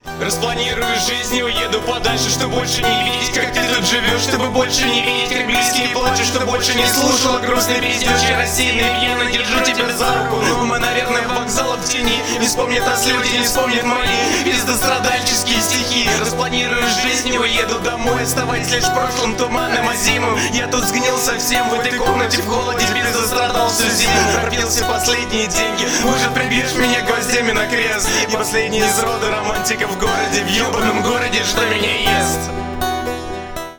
• Качество: 128, Stereo
грустные
русский рэп
печальные